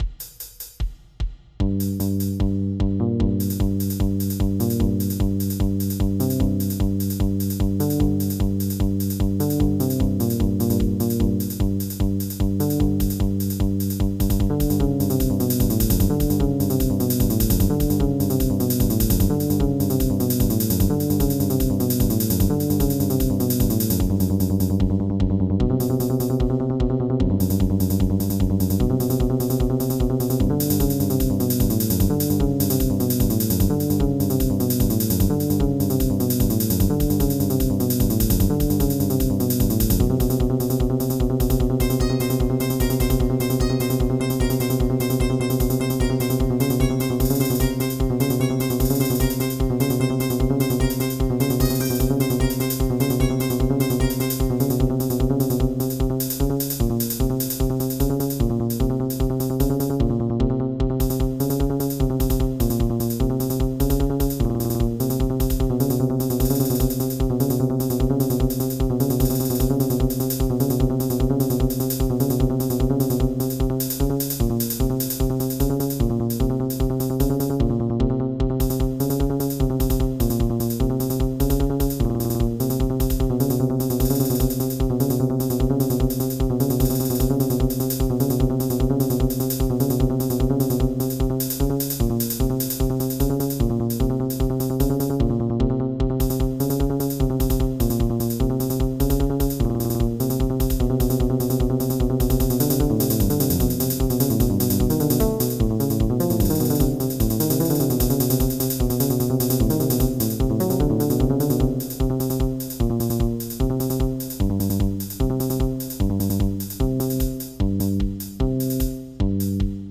MIDI Music File